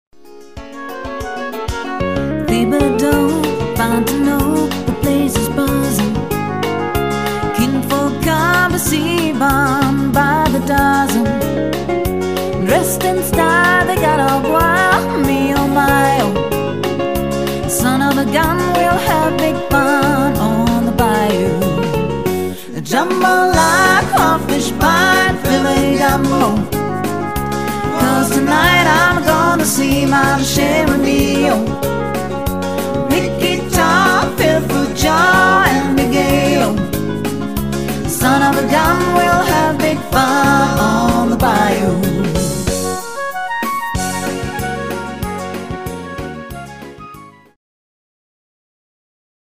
Tanz und Unterhaltungsmusik
• Coverband